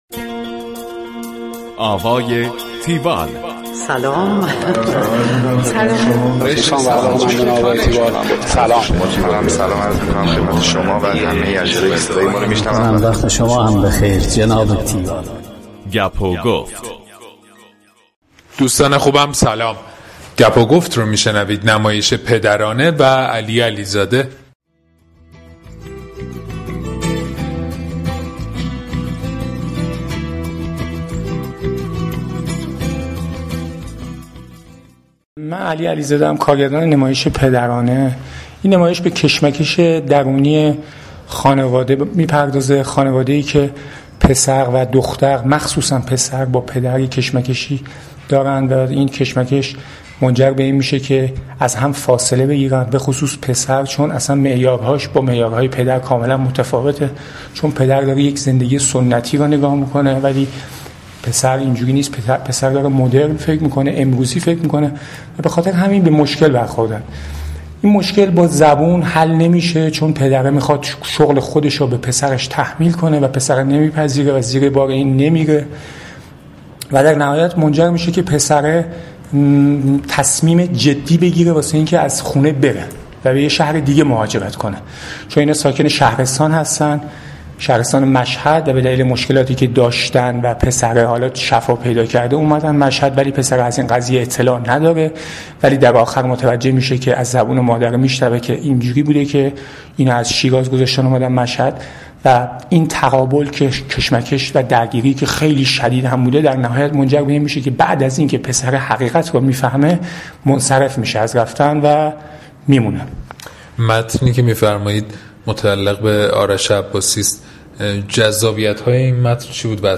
tiwall-interview-alializadeh.mp3